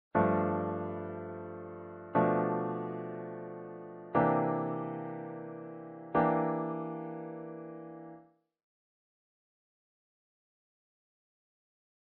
First inversion / blues gold. There's two fairly common first inversion dominant chords used in playing blues. Both are voiced to bring some added color to the chords as the 9th colortone is featured.
These two first inversion shapes are also structured as half diminished 7th chords, thus they each diatonically qualify as Seven in major and diatonic Two in minor.